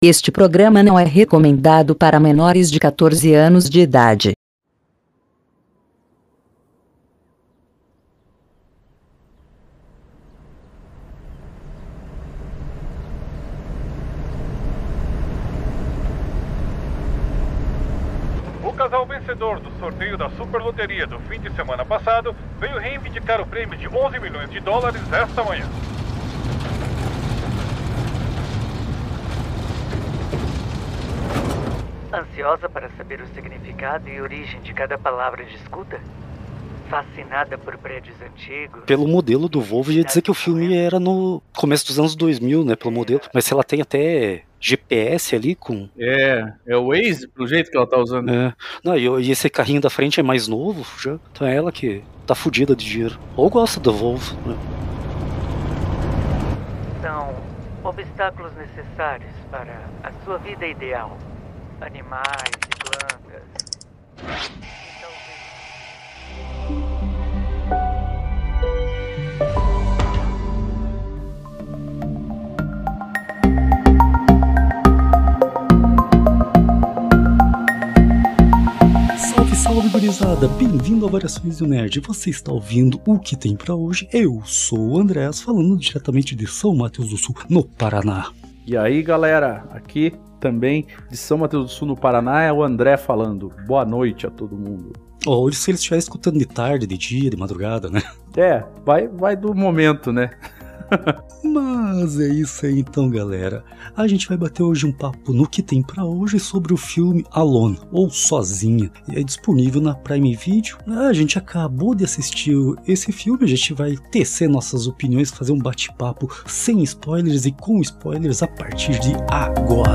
Nesse programa batemos um papo logo após assistirmos Sozinha (Alone), um bom suspense disponível na Prime Video.